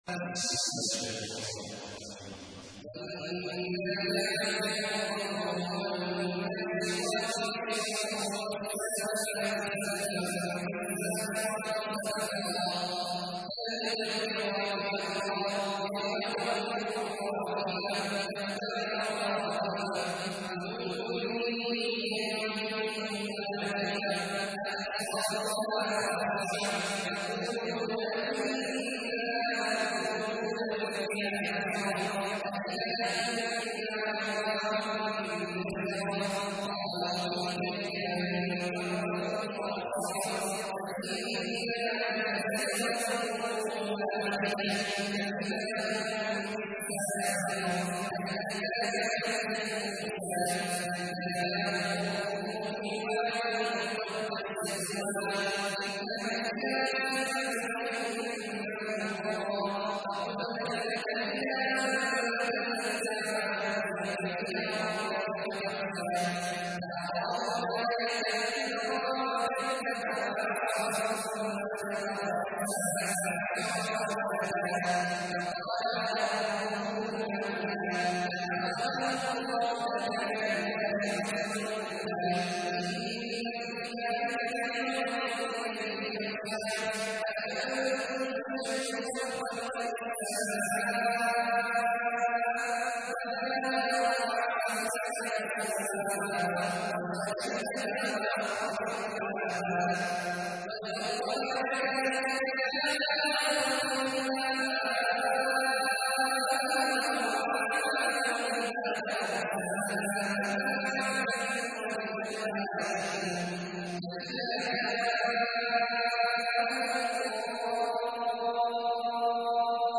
تحميل : 79. سورة النازعات / القارئ عبد الله عواد الجهني / القرآن الكريم / موقع يا حسين